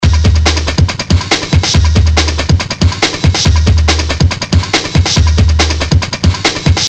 Calling all breakbeat fans...